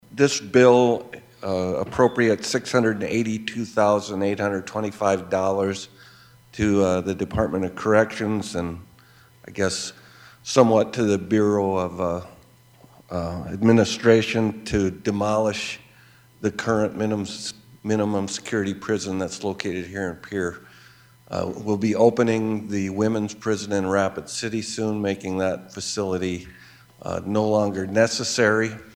Senator Jim Mehlhaff with a second women’s prison nearing completion and it being in poor condition, it’s simply not needed…